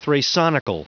Prononciation du mot thrasonical en anglais (fichier audio)
thrasonical.wav